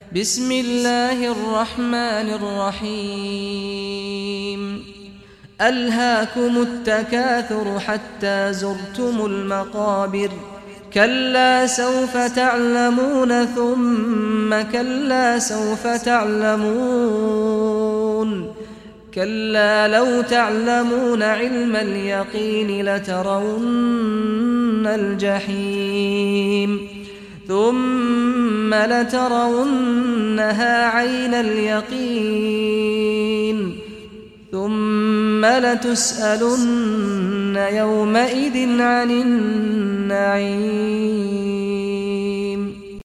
Surah At-Takathur Recitation by Sheikh Saad Ghamdi
Surah At-Takathur, listen or play online mp3 tilawat / recitation in Arabic in the beautiful voice of Sheikh Saad al Ghamdi.